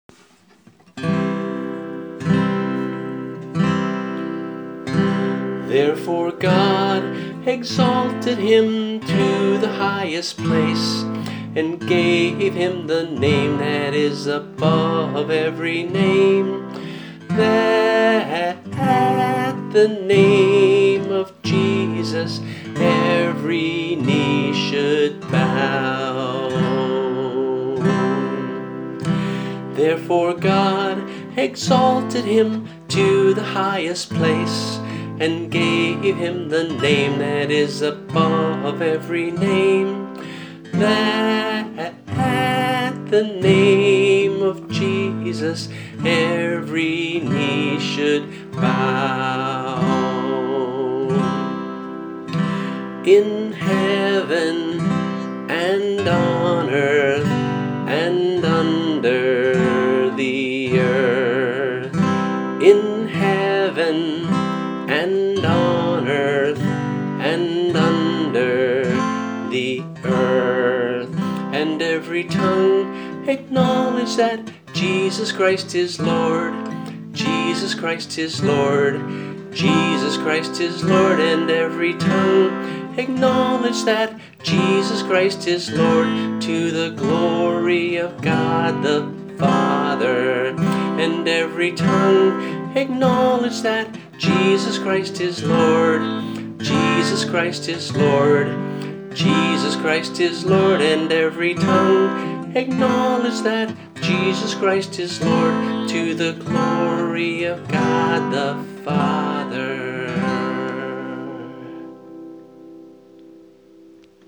voice & guitar